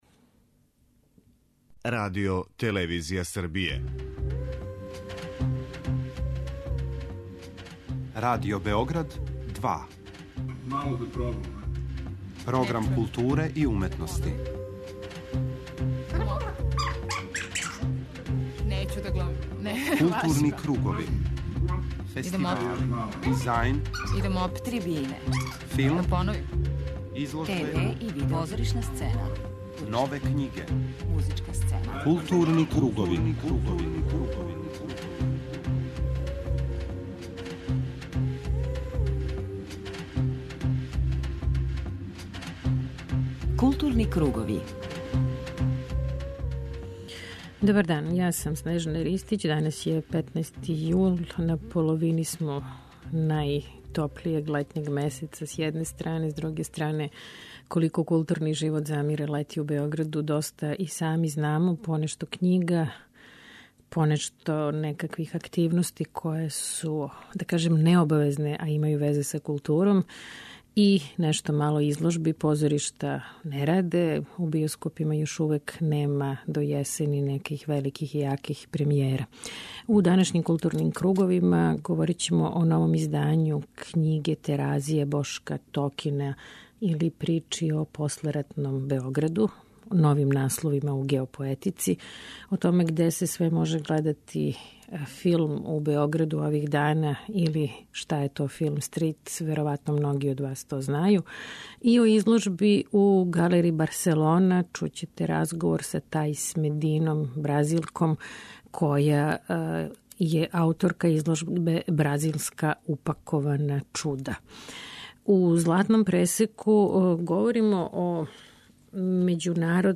преузми : 54.01 MB Културни кругови Autor: Група аутора Централна културно-уметничка емисија Радио Београда 2.